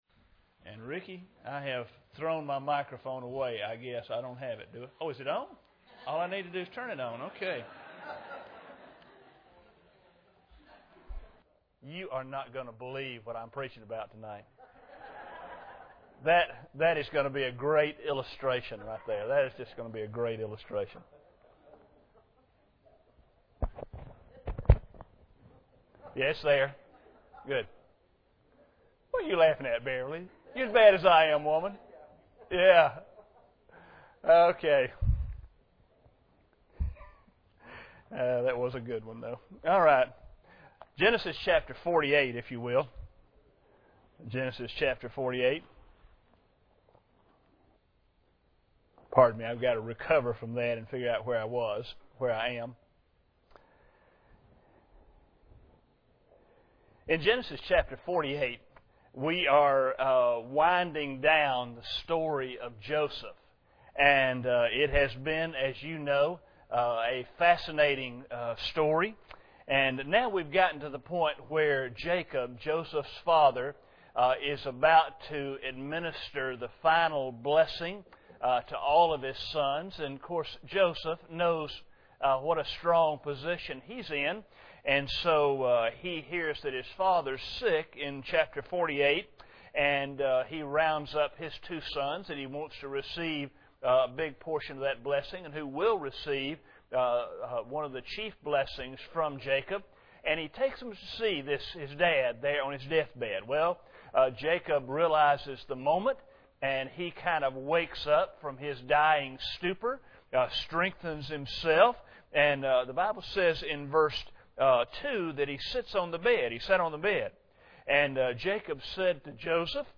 Passage: Genesis 48:1-22 Service Type: Sunday Evening